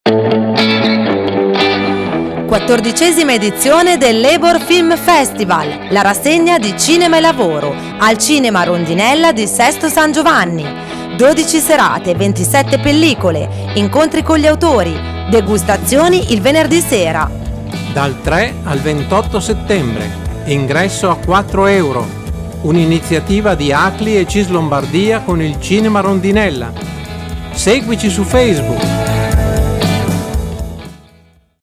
Spot radio – XIV Labour Film Festival – CISL Lombardia
Di seguito lo spot radio realizzato in occasione del XIV Labour Film Festival. Sarà trasmesso da Radio Marconi dal 3 settembre all’11 ottobre 2018.